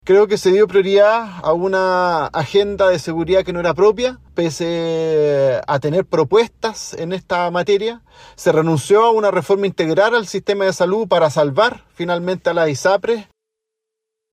El diputado Matías Ramírez afirmó que existe responsabilidad del gobierno y de toda la coalición en la derrota electoral, pero enfatizó en que se “desvió” el programa original.